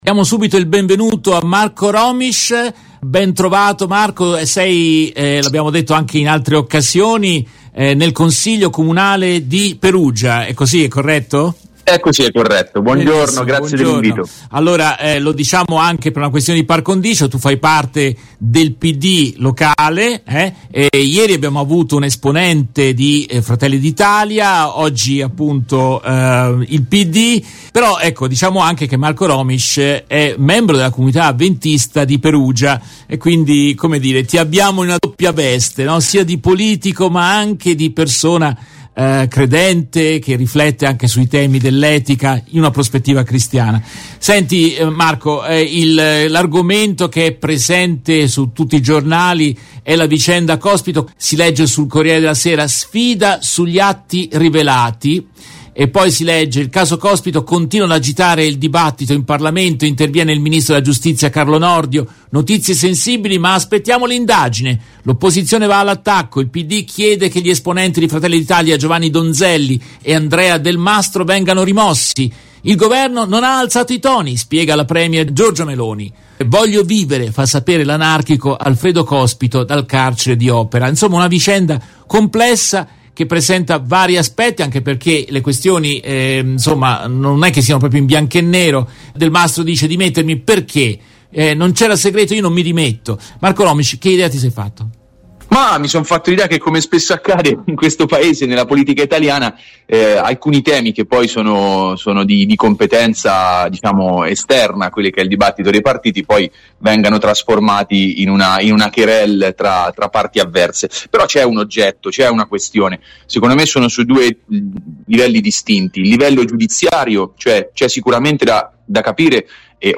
In questa intervista tratta dalla diretta RVS del 02 febbraio 2023